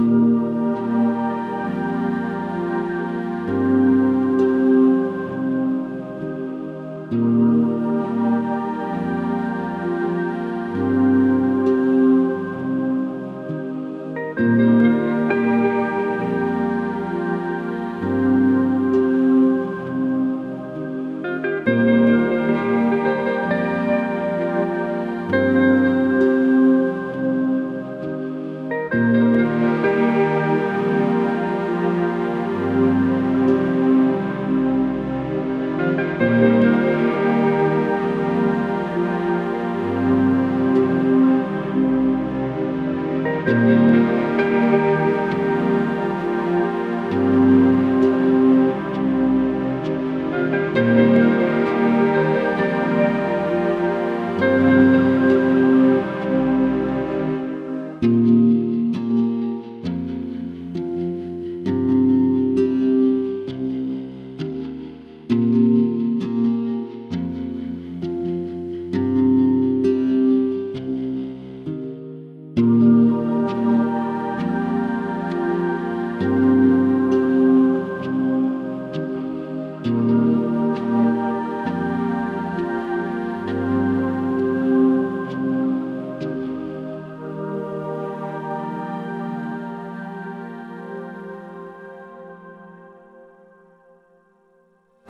Back Ground Music